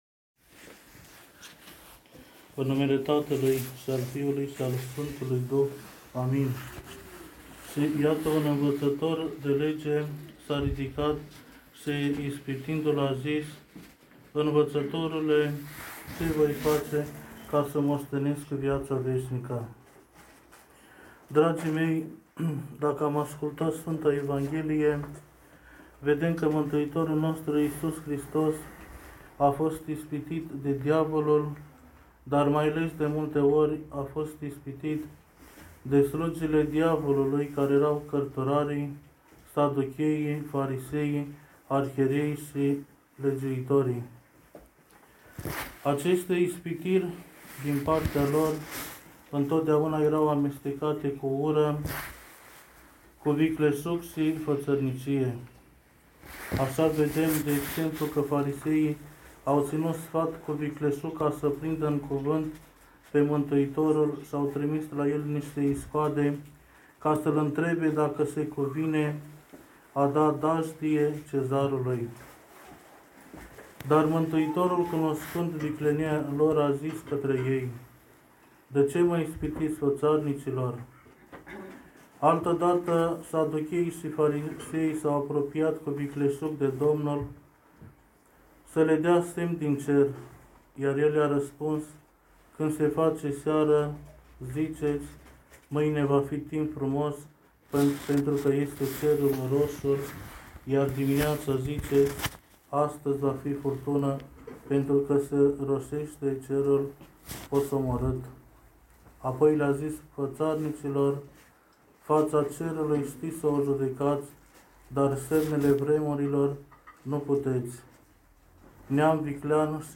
predică